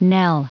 Prononciation du mot knell en anglais (fichier audio)
Prononciation du mot : knell